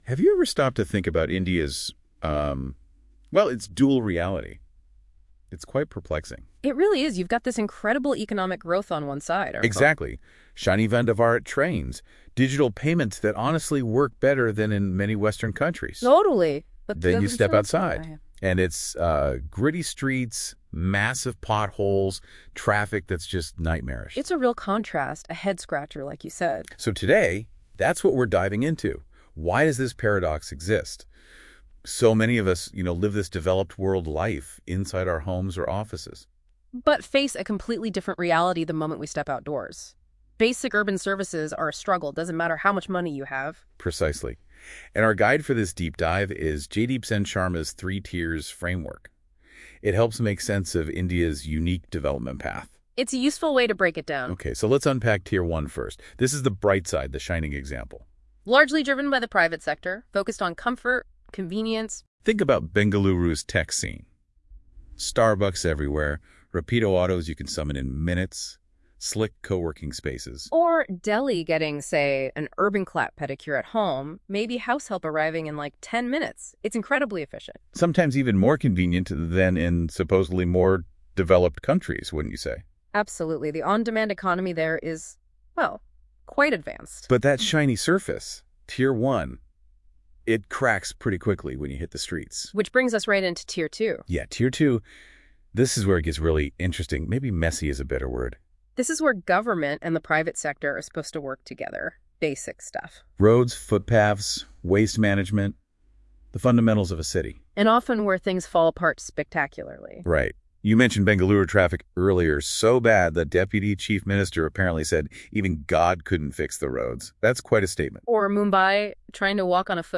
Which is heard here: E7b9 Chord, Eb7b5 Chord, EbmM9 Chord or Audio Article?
Audio Article